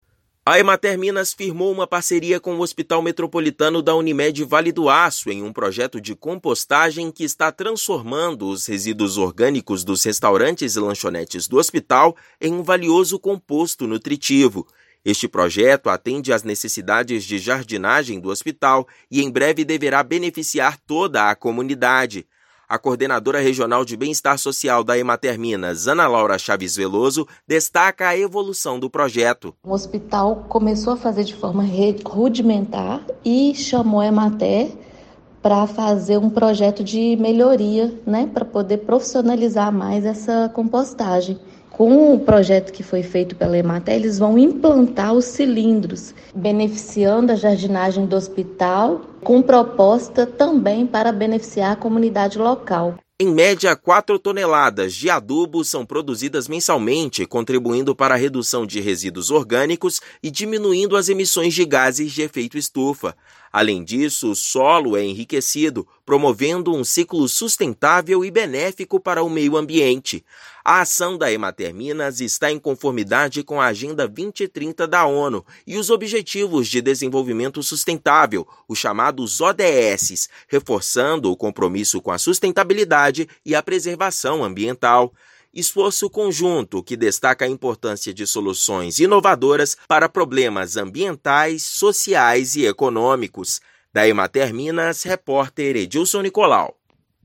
Iniciativa, desenvolvida em parceria com a Emater- MG, produz adubos para os jardins da instituição hospitalar, com previsão de beneficiar também toda comunidade local. Ouça matéria de rádio.